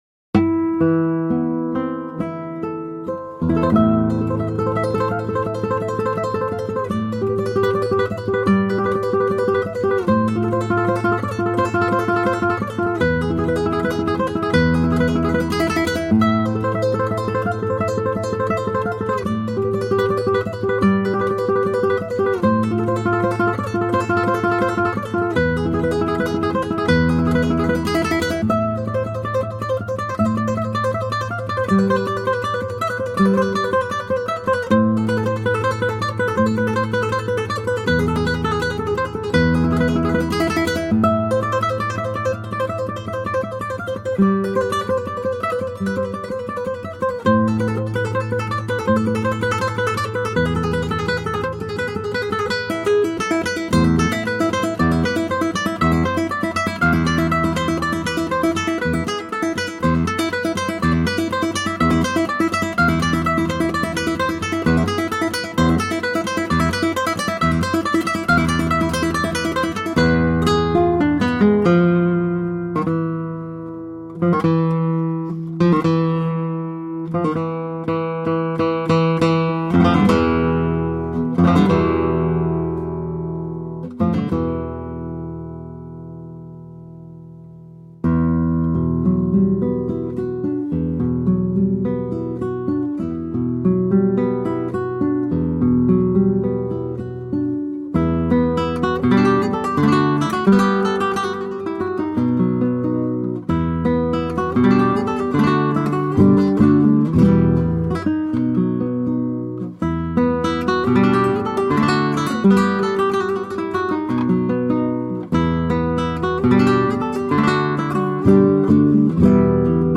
Outstanding classical guitar.